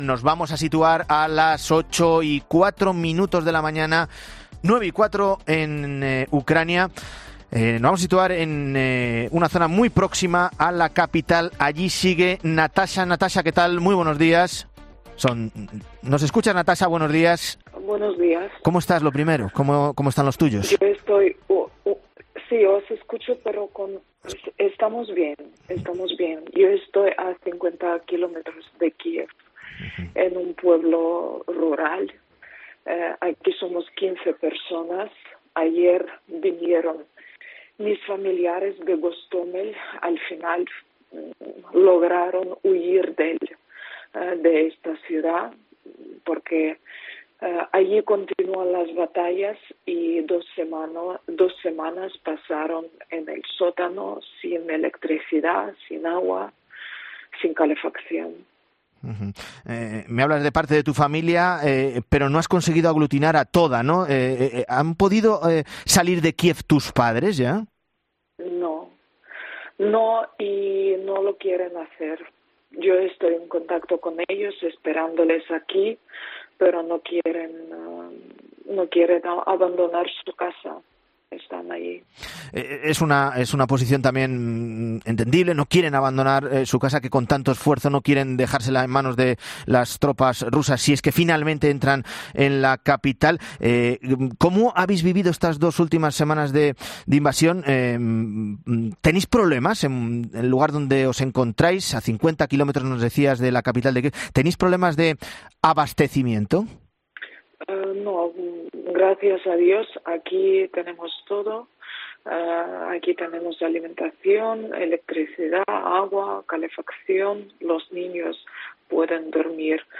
Esta joven ucraniana cuenta en La Mañana Fin de Semana cómo ha tenido que acoger a 15 familiares en su casa que huían de los bombardeos en Hostómel